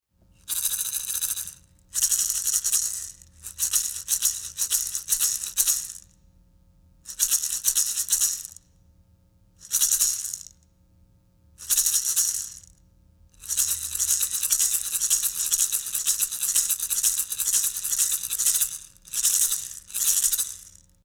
These are a slightly larger and louder version of the IAT 0799J and produce a fuller sound.
Maracas no feathers